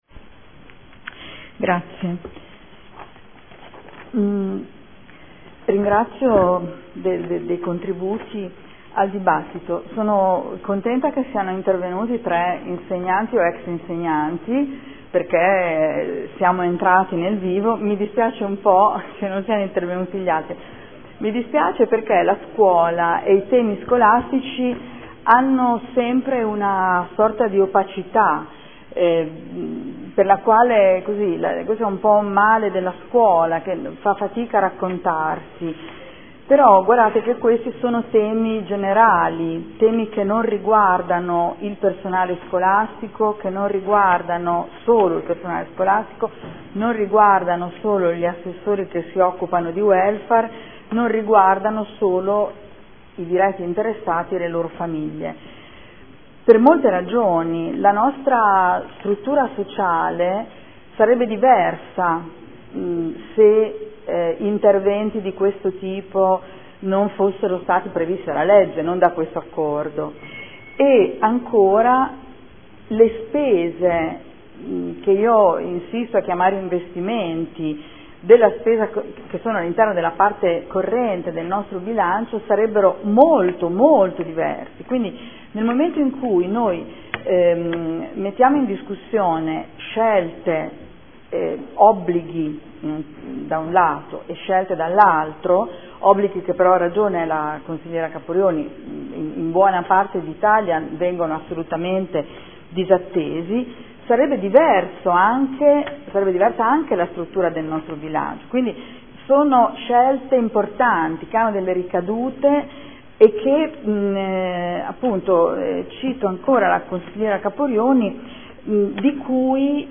Seduta del 20/03/2014 Replica. Approvazione dell’accordo di distretto inerente l’integrazione scolastica degli alunni disabili nei nidi e scuole di ogni ordine e grado